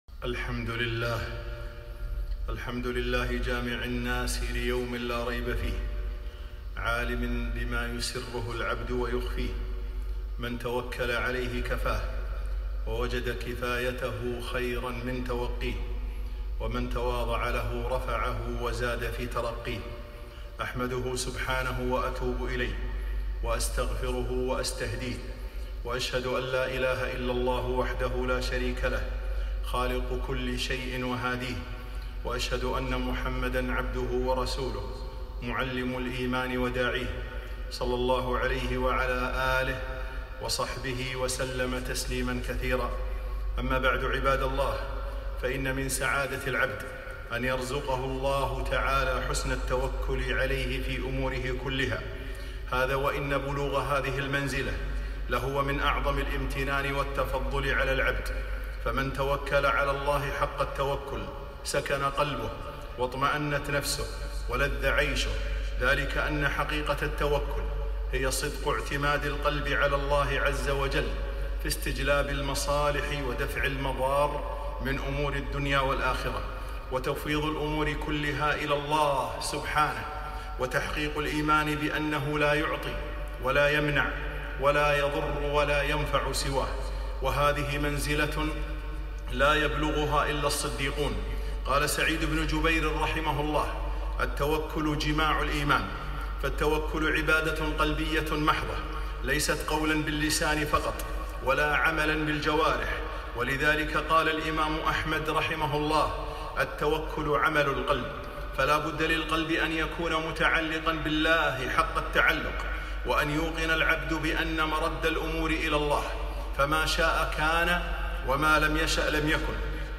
خطبة - ثمرات التوكل